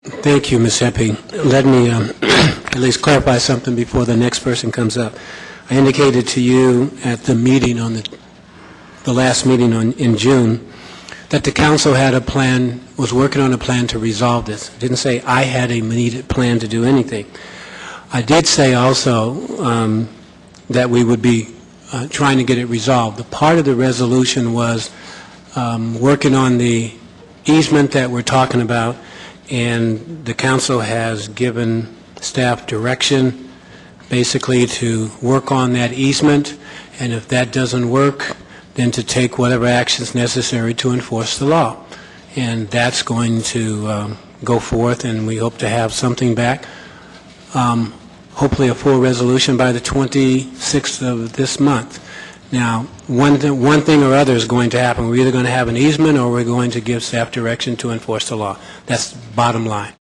Osby Davis, Mayor of Vallejo